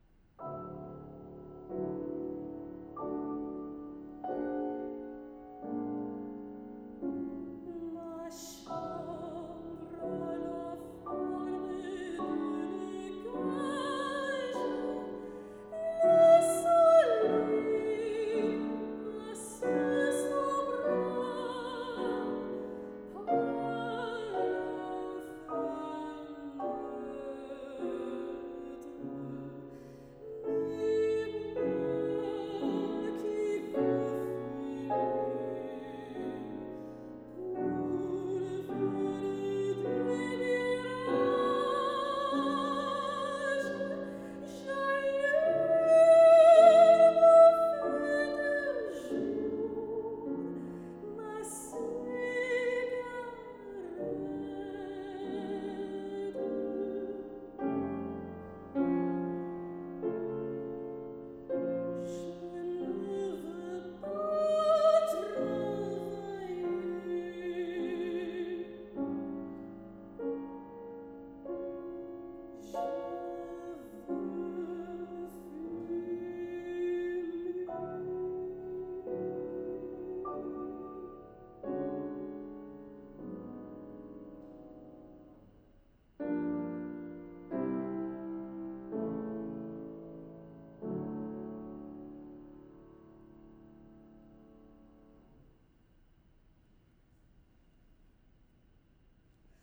soprano
Solo song: